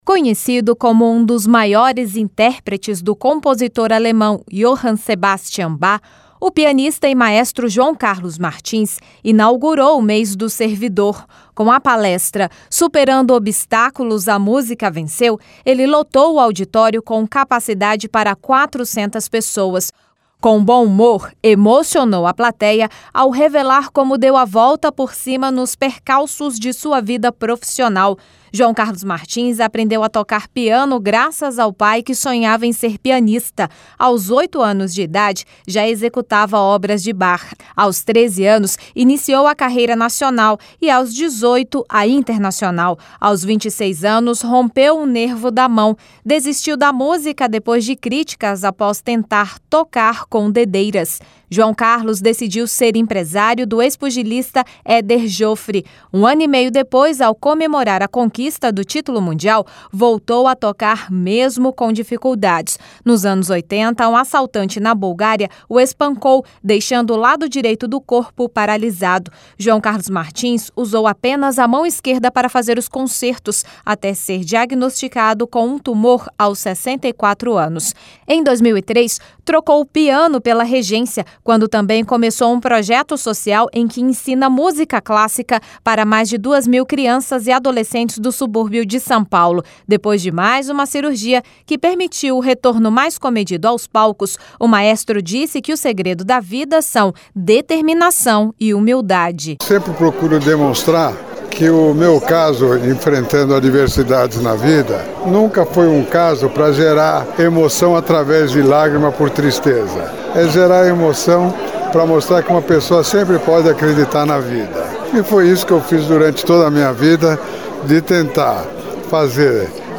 Rádio Senado - Ao Vivo